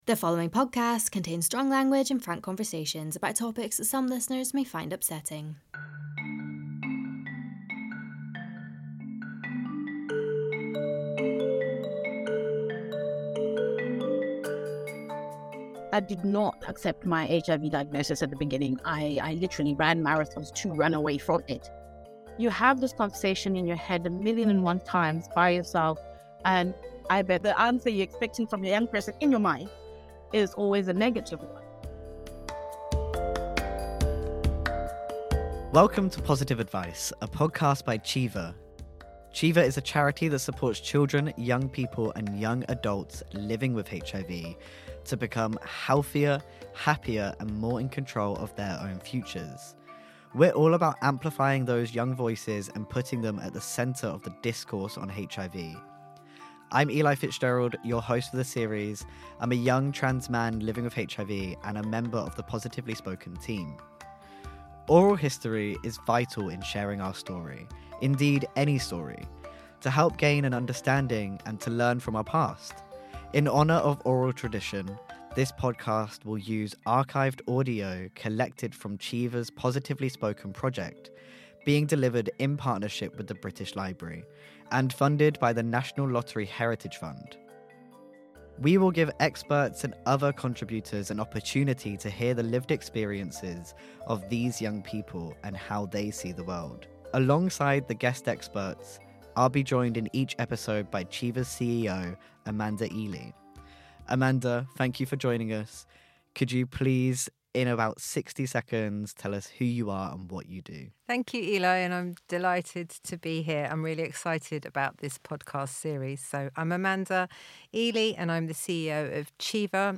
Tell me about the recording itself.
this series explores life growing up with HIV, using stories recorded at the International AIDS Conference in Montreal, July 2022